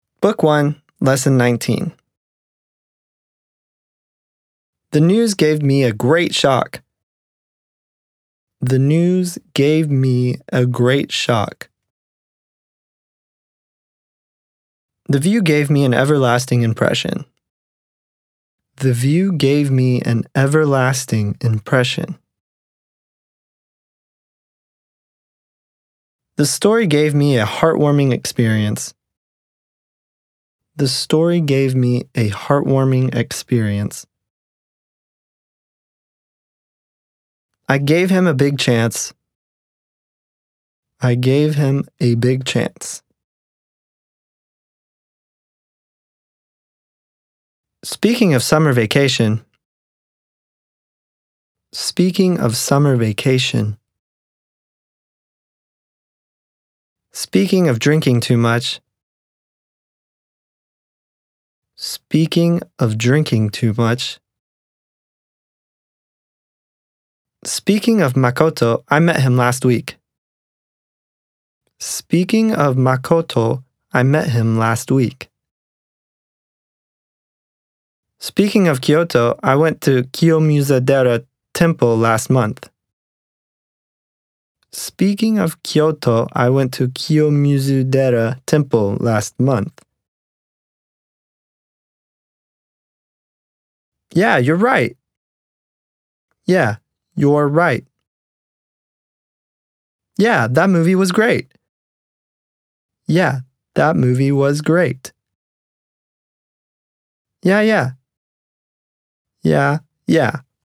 レクチャー音声サンプル（約4.2MB）
「スーパー・センテンス」「コンビニ・フレーズ」「クール・イングリッシュ」の例文のネイティブによる音声です。ナチュラル＆スローが繰り返されます。機械式ではありません。